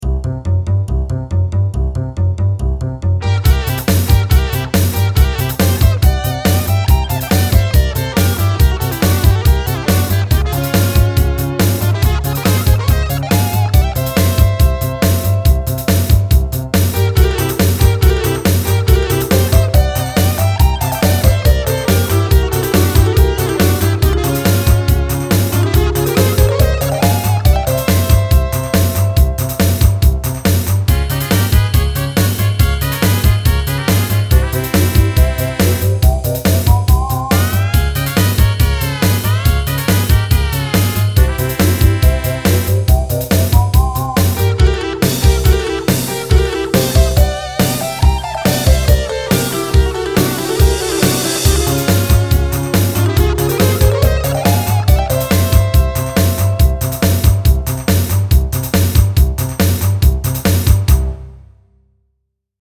Catchy Guitar